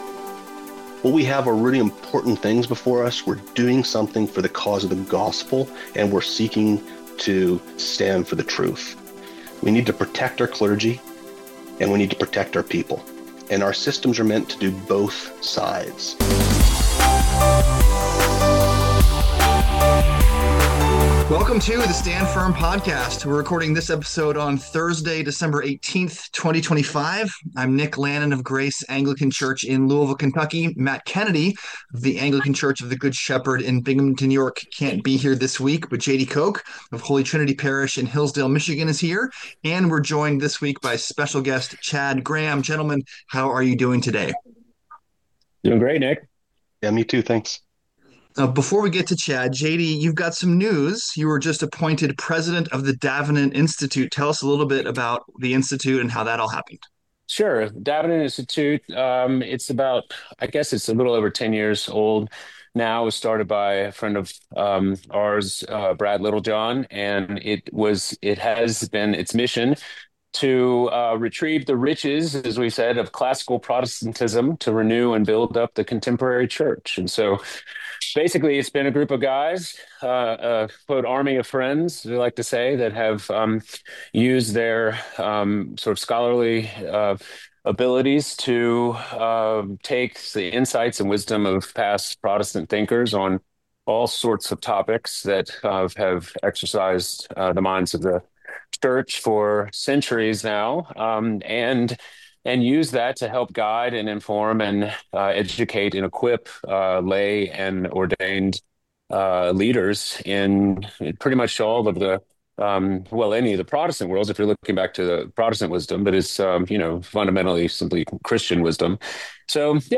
#282: Not Guilty on All Charges: A Conversation